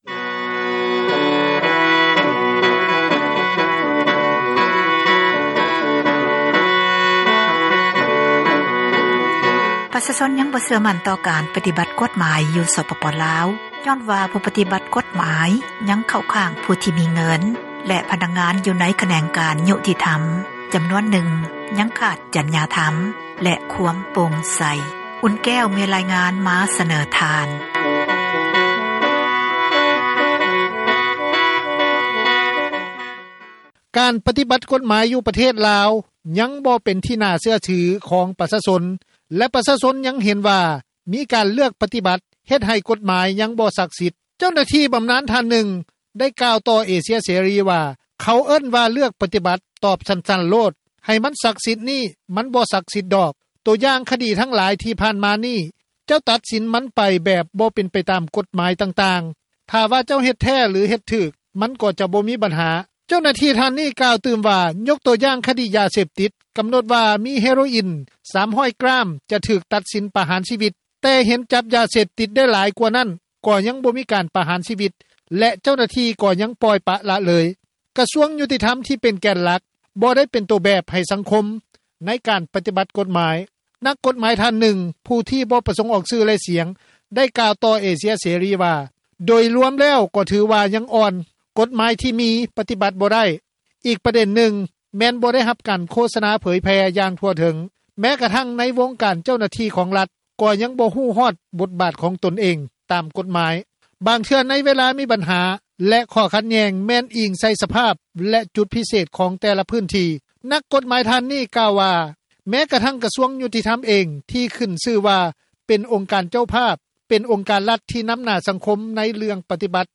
ເຈົ້າໜ້າທີ່ບຳນານ ທ່ານນຶ່ງ ໄດ້ກ່າວຕໍ່ວິທຍຸ ເອເຊັຽ ເສຣີ ວ່າ:
ປະຊາຊົນ ທ່ານນຶ່ງ ໄດ້ໃຫ້ສັມພາດ ຕໍ່ວິທຍຸ ເອເຊັຽ ເສຣີວ່າ:
ທ່ານ ໄພວີ ສີບົວວິພາ, ຣັຖມົນຕຣີ ກະຊວງຍຸຕິທັມ ໄດ້ກ່າວ ຢູ່ໃນກອງປະຊຸມສະພາແຫ່ງຊາຕ ໃນກາງປີ 2022 ຕອນນຶ່ງວ່າ: